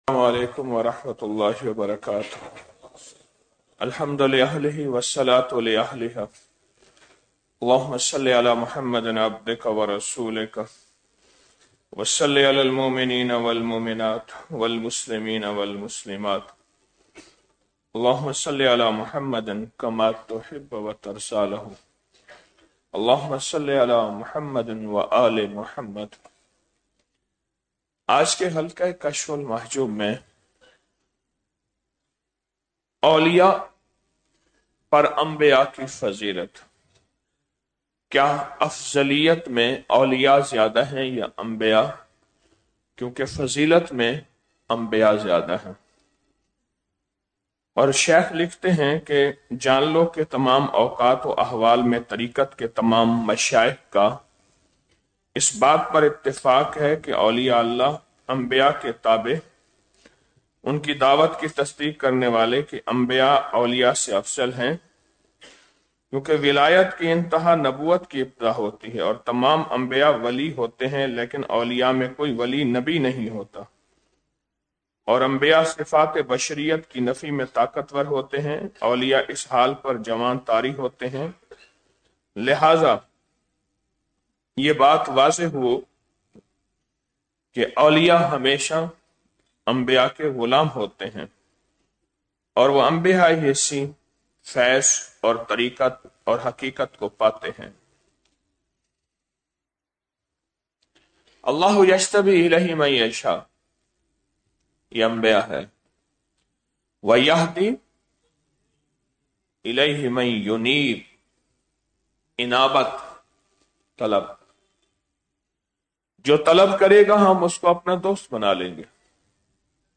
طبیعت اور شریعت|| 25 رمضان المبارک بعد نماز فجر- 15 مارچ 2026ء